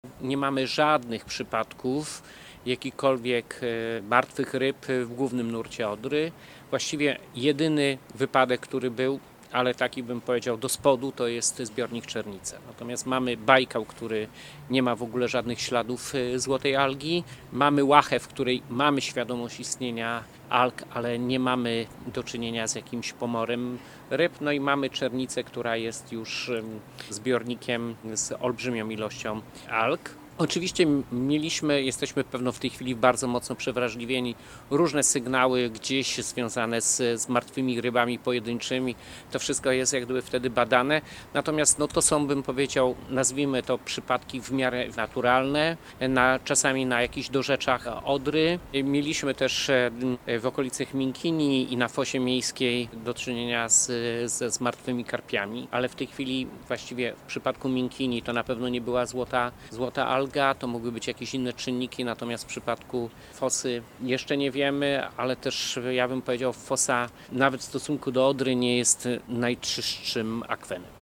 -Na tę chwilę nie mamy martwych ryb w głównym nurcie Odry. Przygotowujemy się do sezonu letniego, by uniknąć sytuacji sprzed roku – mówił w trakcie konferencji Jarosław Obremski – Wojewoda Dolnośląski nt. sytuacji rzeki.
– Mamy do czynienia z przyduchą i ze złotymi algami w Kanale Gliwickim. Nie mamy martwych ryb w głównym nurcie Odry, dodaje wojewoda.